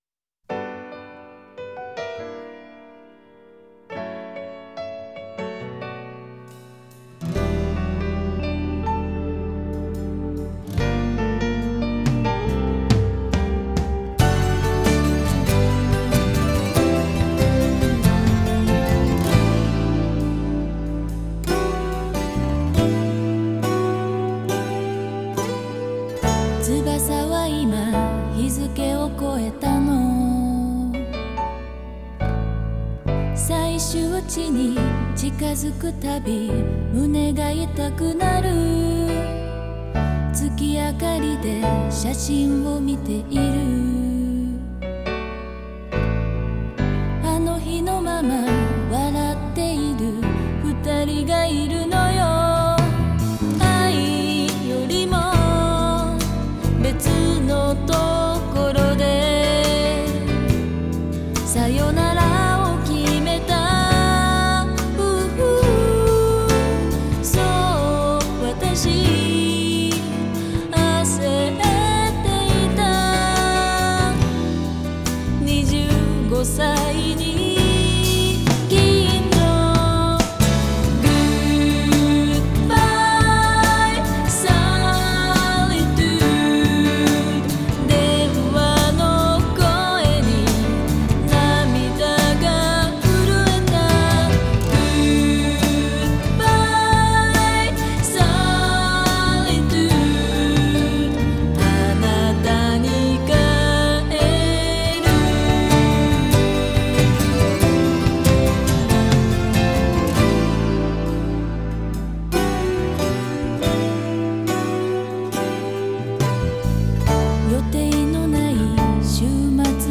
Genre: DOMESTIC(J-POPS).